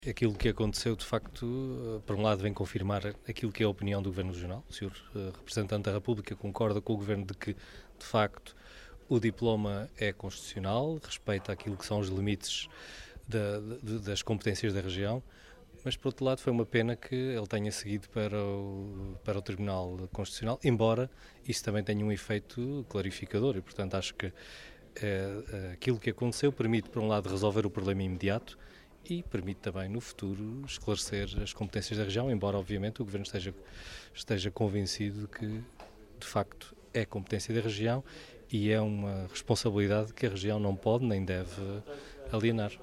O Secretário Regional do Ambiente e do Mar comentou hoje, em Santa Cruz das Flores, o facto de o Representante da República ter enviado para fiscalização sucessiva um diploma, aprovado no parlamento açoriano, sobre prospeção e exploração de recursos geológicos marinhos no mar do arquipélago.